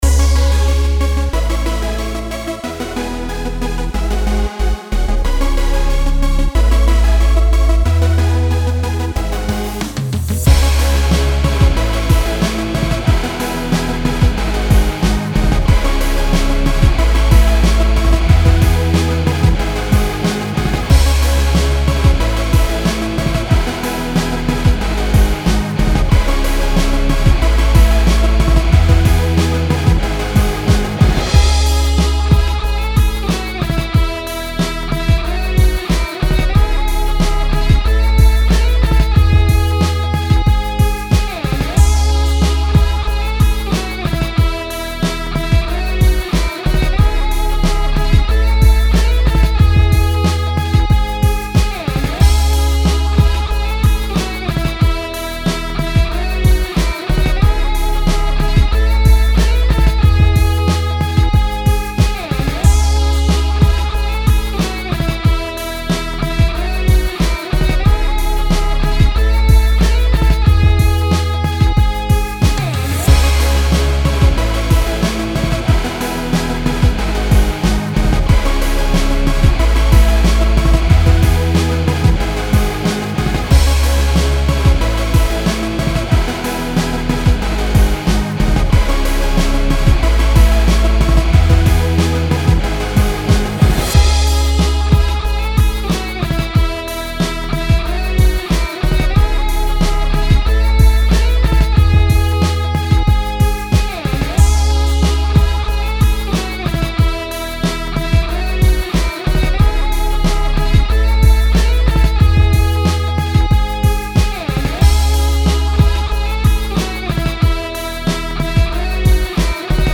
Minus Tracks, Music, Samples, Loops